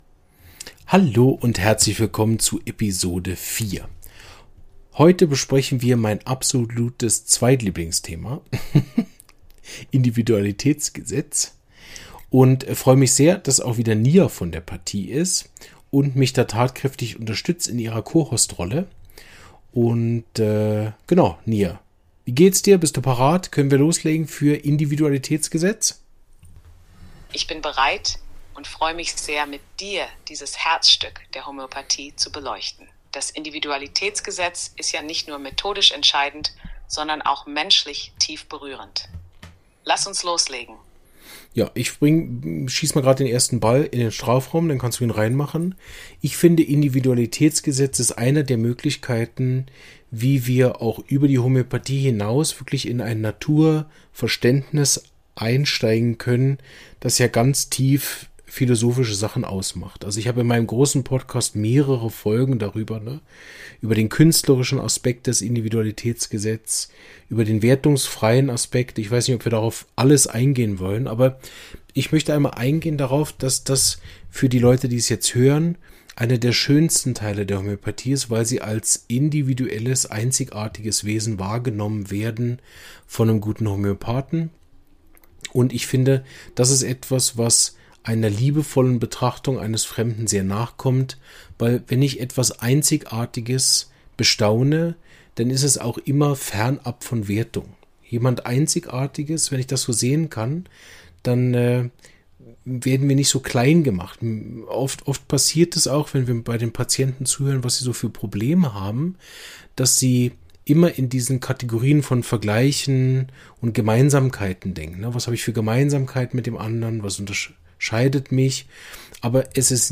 Das Individualitätsprinzip – Jeder Mensch ist einzigartig ~ Homöopathie erklärt – im Dialog mit einem KI-System Podcast